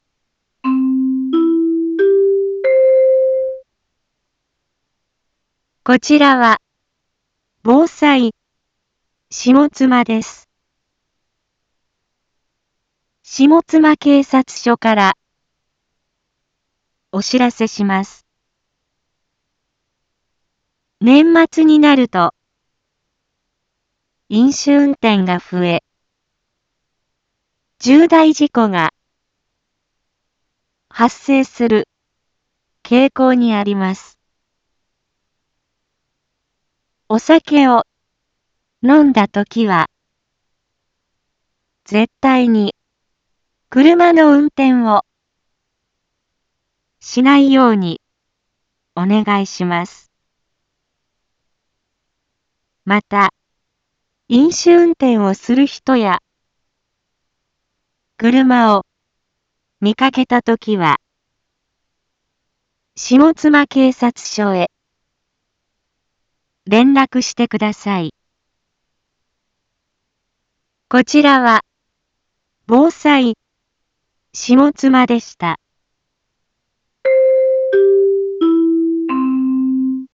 一般放送情報
Back Home 一般放送情報 音声放送 再生 一般放送情報 登録日時：2021-12-10 17:31:24 タイトル：飲酒運転根絶広報 インフォメーション：こちらは、防災下妻です。